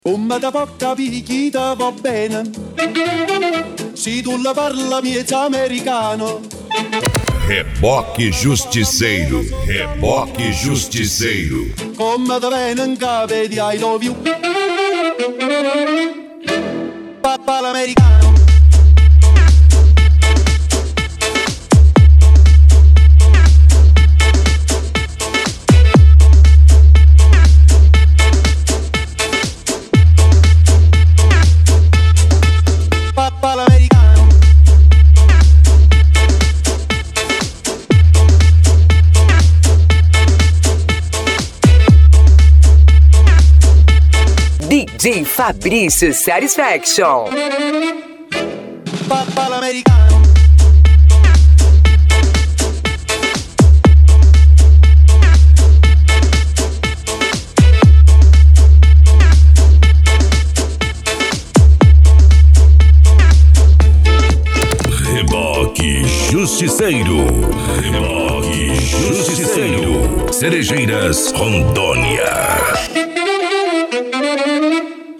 Funk
Sertanejo Universitario